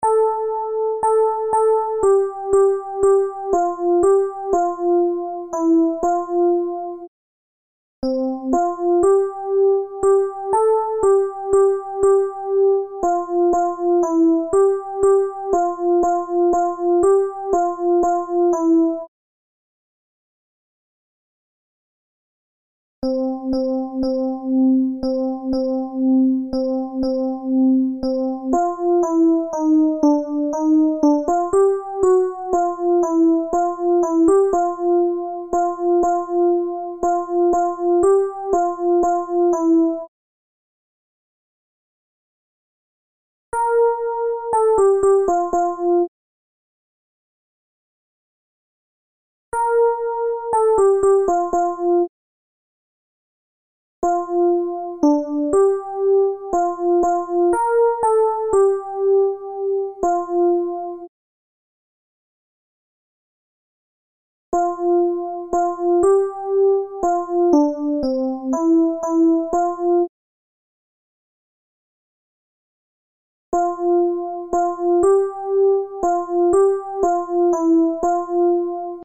Alti
pres_du_tendre_alti.MP3